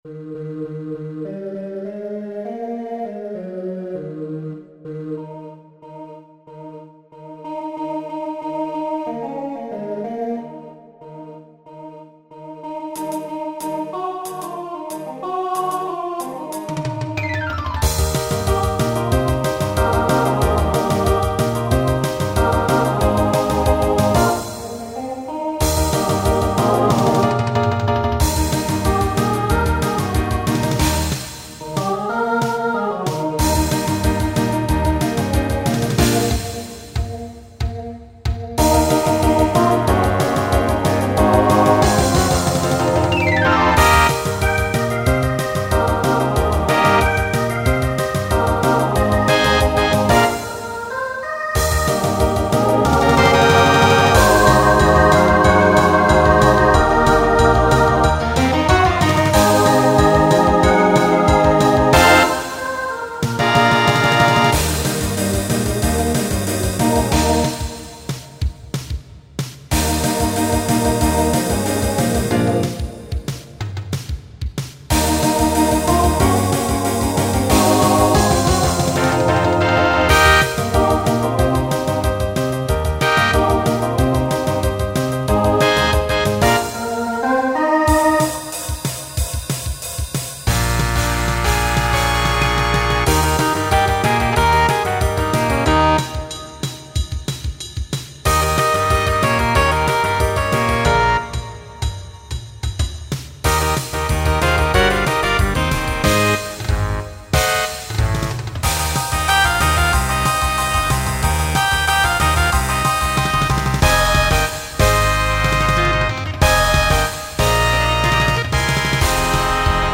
Voicing SATB Instrumental combo Genre Broadway/Film , Rock